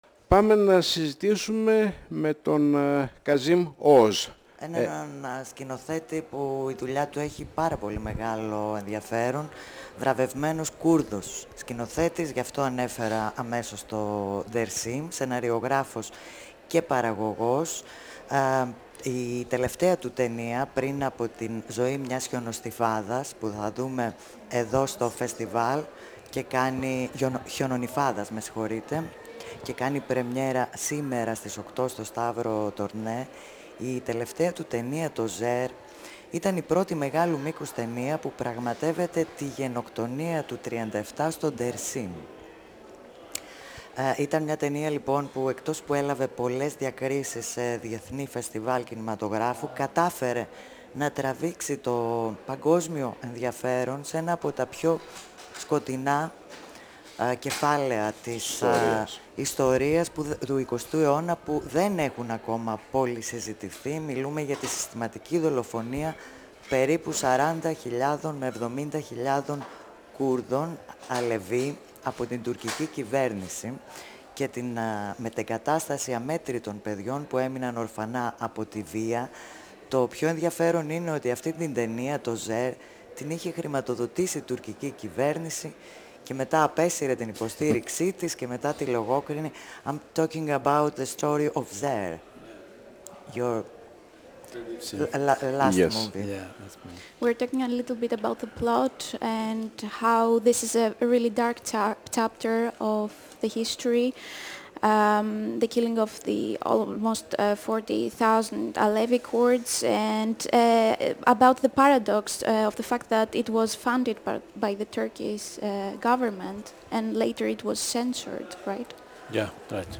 958FM Συνεντεύξεις